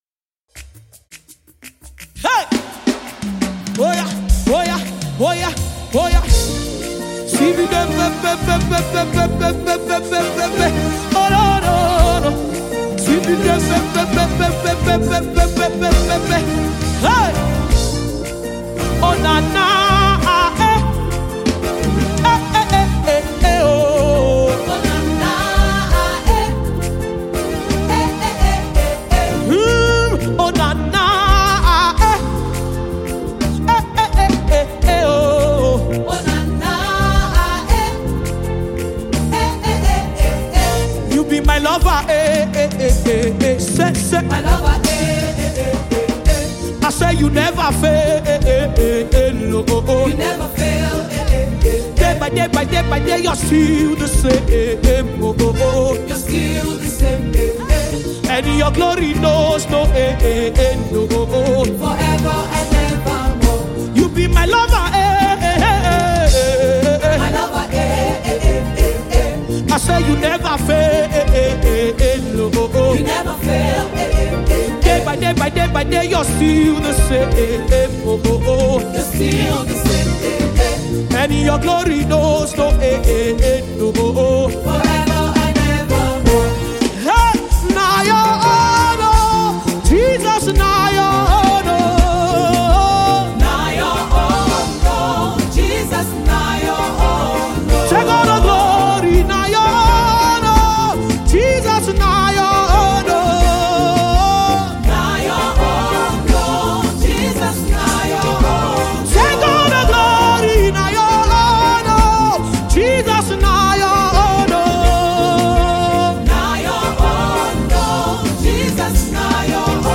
Professional Nigerian gospel singer and songwriter